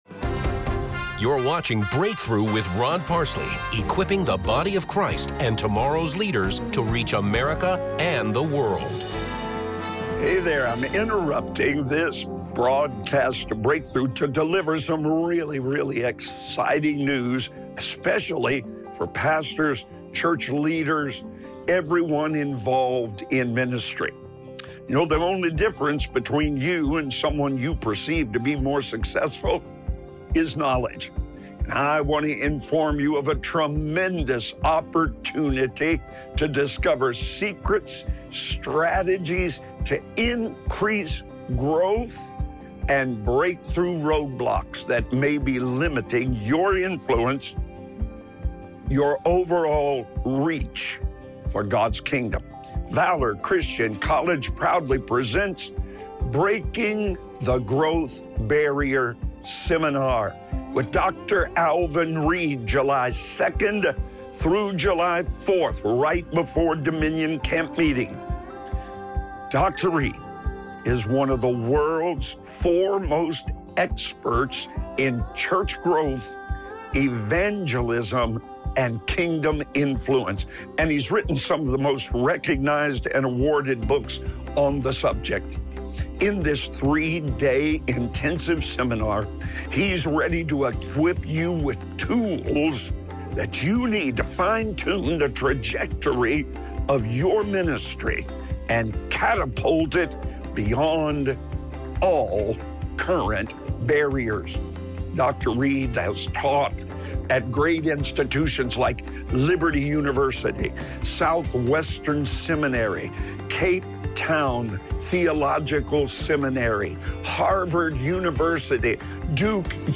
Audio only from the daily television program Breakthrough hosted by Pastor Rod Parsley